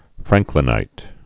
(frăngklĭ-nīt)